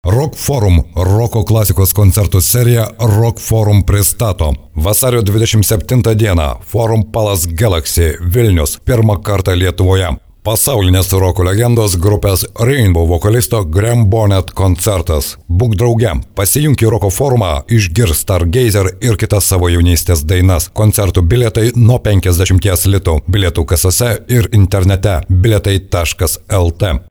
Sprecher litauisch für TV / Rundfunk / Industrie.
Sprechprobe: Werbung (Muttersprache):
Professionell voice over artist from Lithuania.